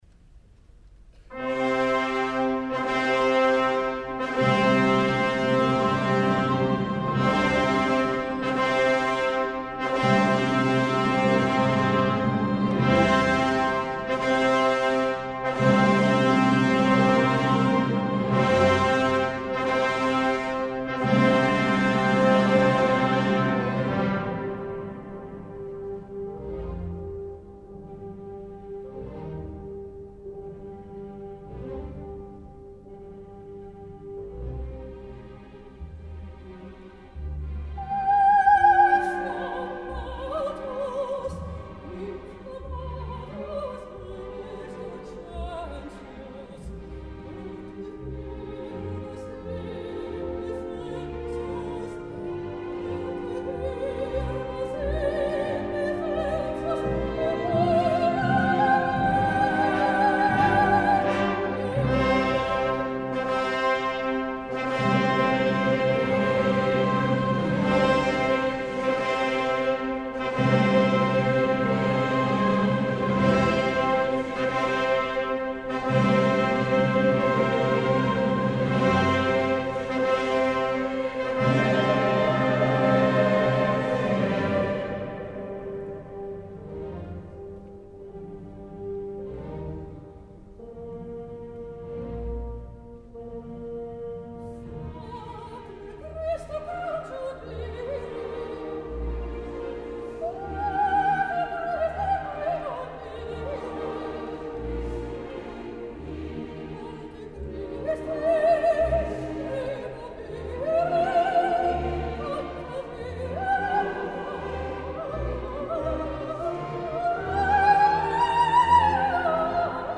sopraan Muziekfragmenten
Rossini – Stabat Mater -Inflamatus- Hooglandse Kerk -Leiden